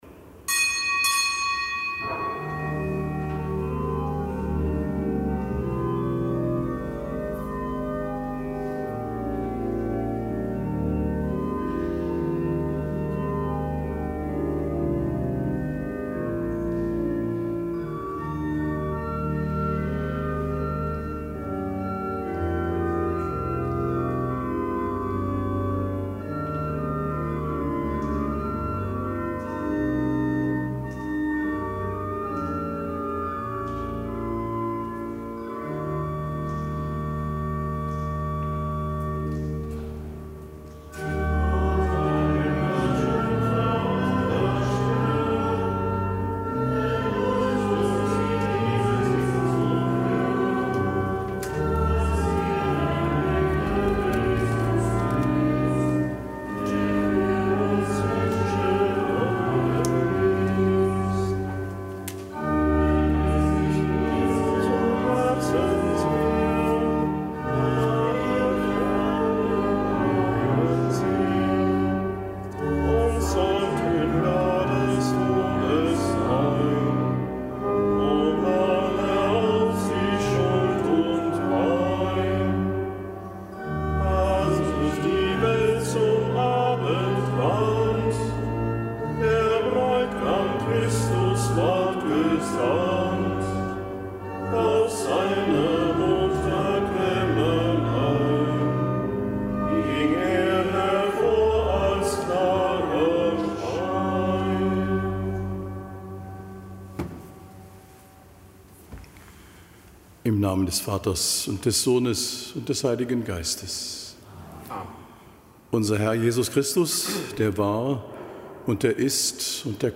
Kapitelsmesse aus dem Kölner Dom am Samstag der zweiten Adventswoche. Nichtgebotener Gedenktag Seligen Franziska Schervier, Jungfrau, Ordensgründerin (DK).